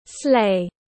Sleigh /sleɪ/